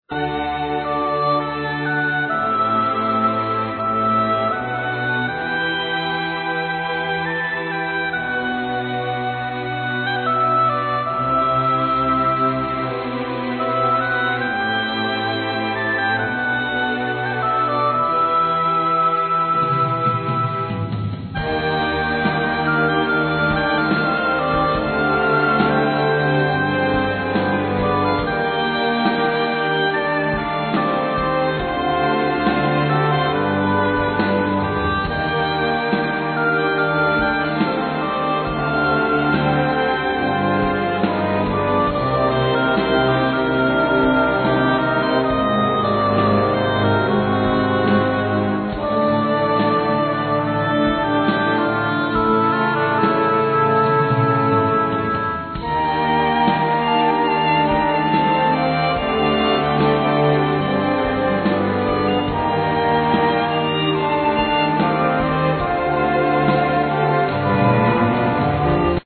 Keyboards, Vocals
Drums, Percussions, Bass guitar, Vocals
Flute
Cello
Violin
Trumpet
Guitar